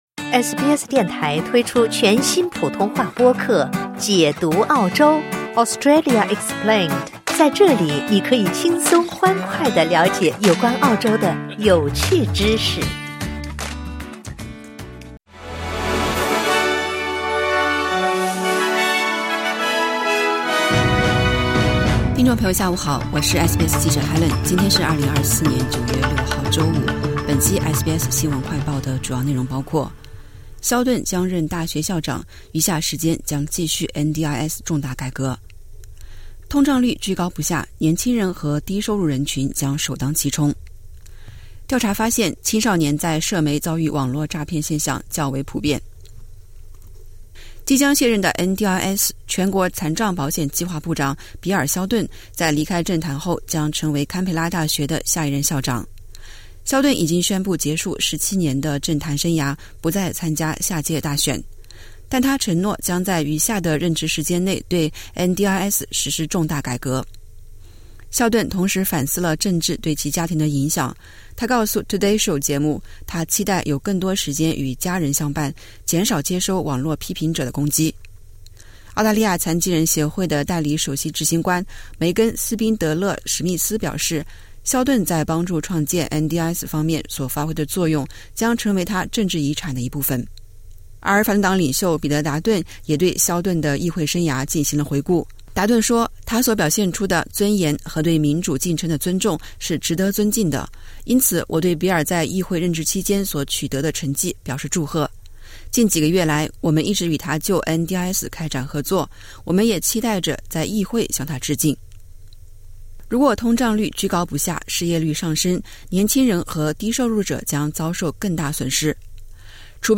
【SBS新闻快报】比尔·肖顿退出政坛后将任堪培拉大学校长